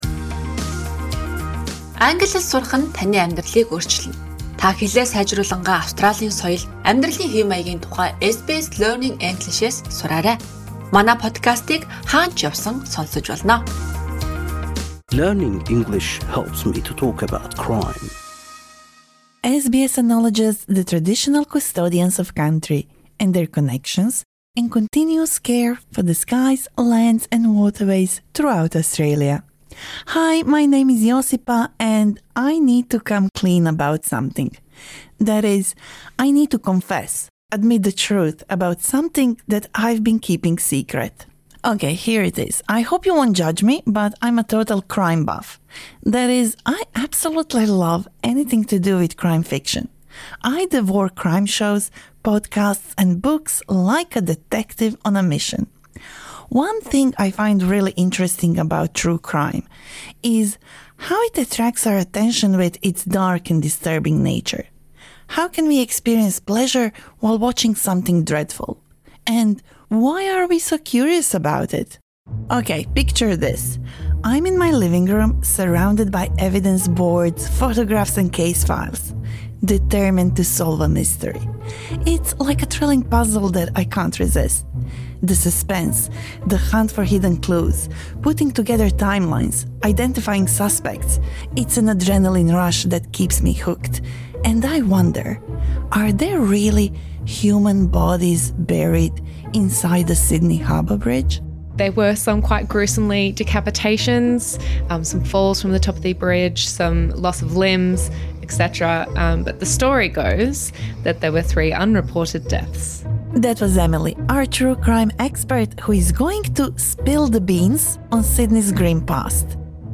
This lesson suits intermediate learners.